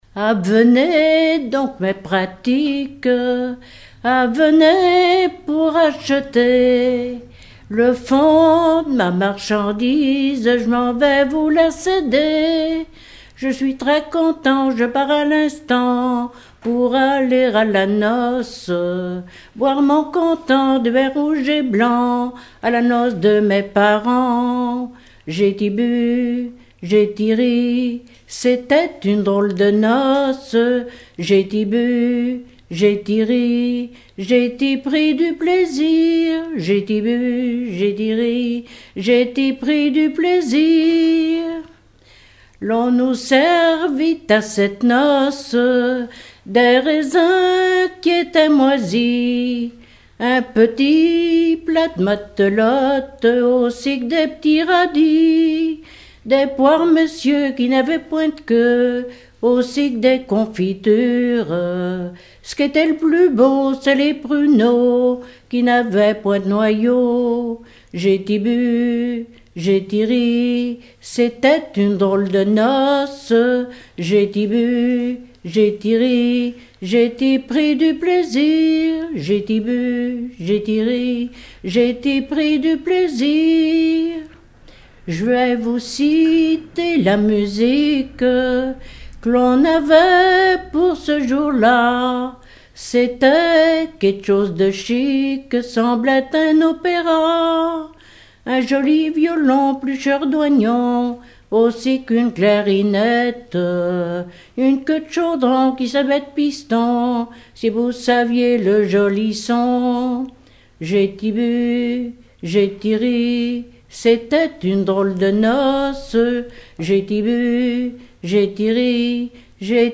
Genre strophique
collectif de chanteurs du canton
Pièce musicale inédite